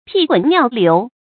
屁滾尿流 注音： ㄆㄧˋ ㄍㄨㄣˇ ㄋㄧㄠˋ ㄌㄧㄨˊ 讀音讀法： 意思解釋： 形容極度恐懼、驚惶；失去自禁。